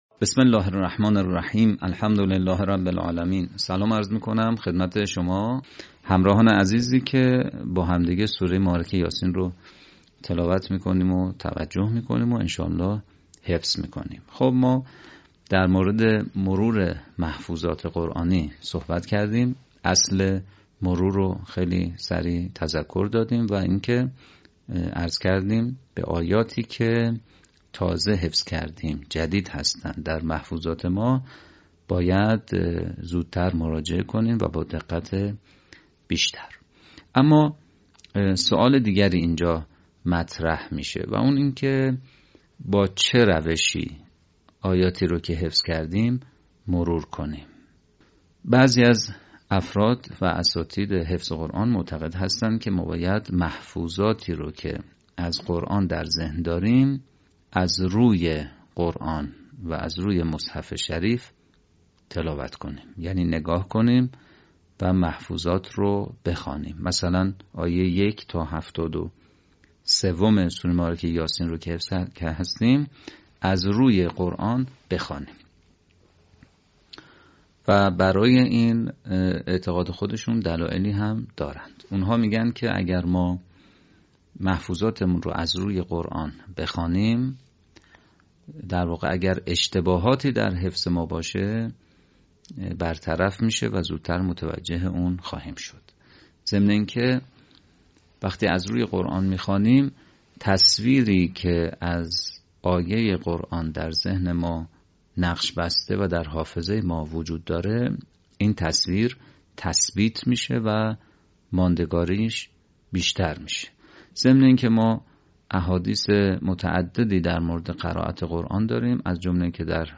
به همین منظور مجموعه آموزشی شنیداری(صوتی) قرآنی را گردآوری و برای علاقه‌مندان بازنشر می‌کند.
آموزش حفظ قرآن